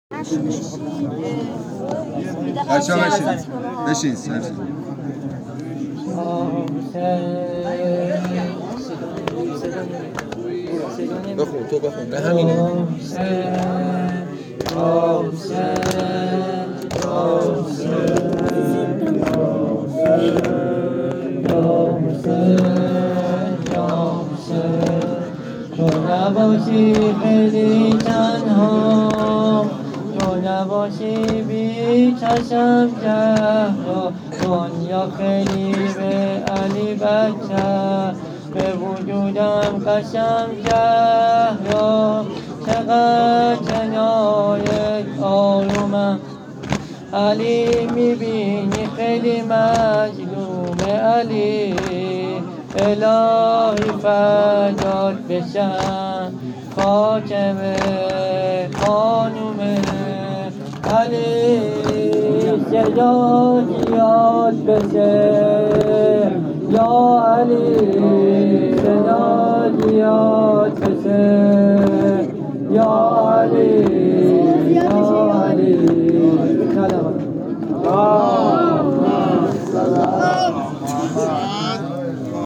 به خاطره همسایگان صدا قطع کردن